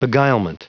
Prononciation du mot beguilement en anglais (fichier audio)
Prononciation du mot : beguilement